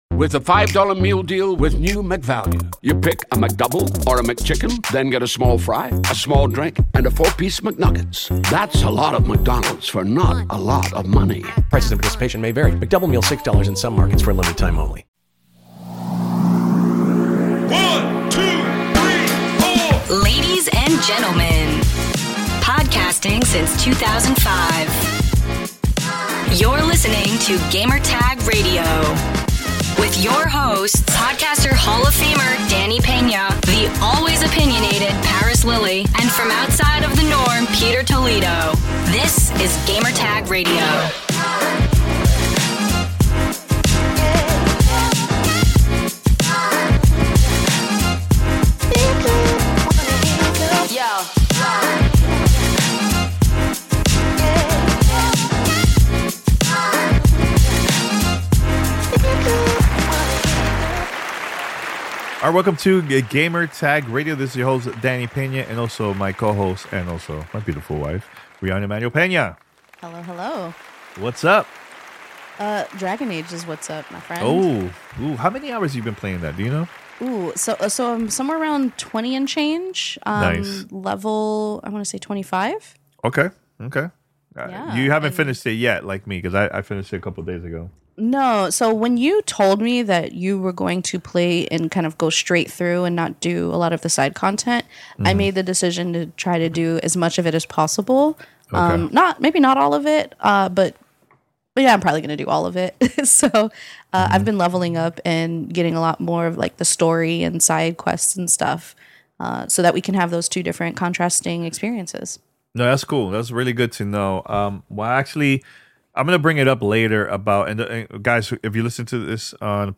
Dragon Age: The Veilguard Review, The EGM Compendium and Alien: Rogue Incursion Interviews